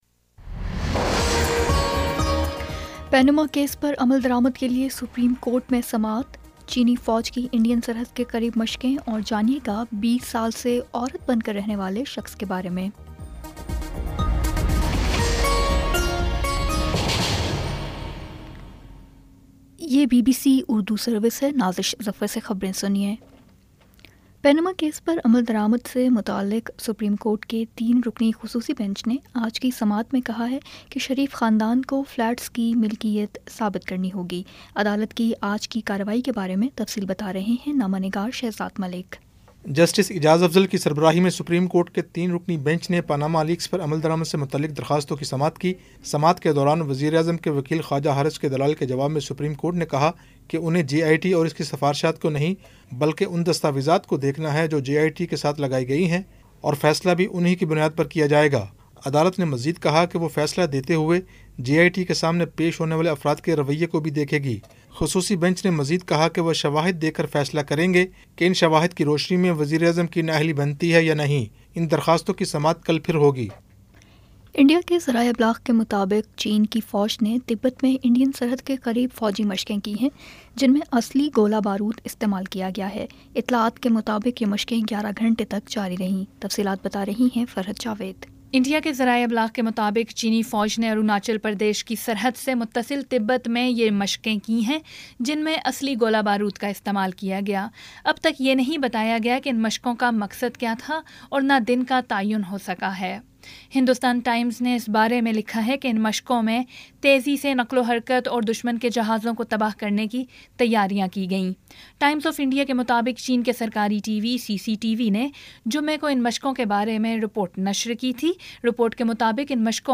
جولائی 18 : شام سات بجے کا نیوز بُلیٹن